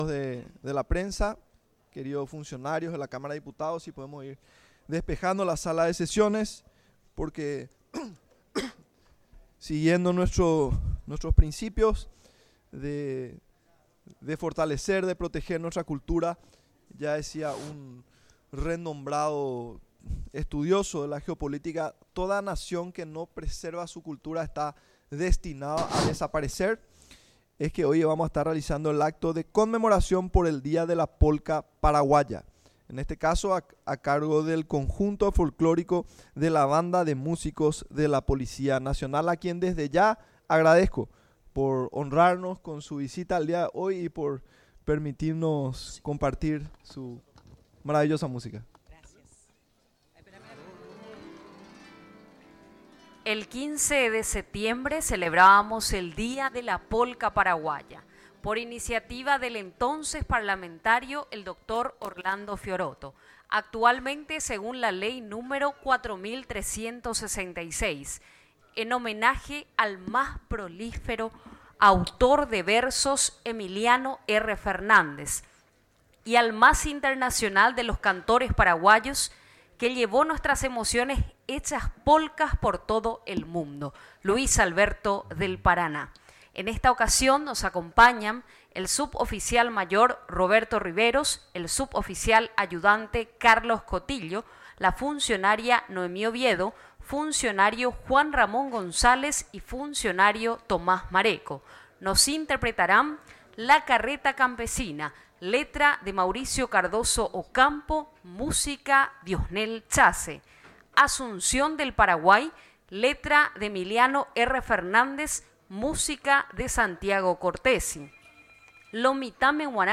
Sesi�n Ordinaria, 16 de setiembre de 2025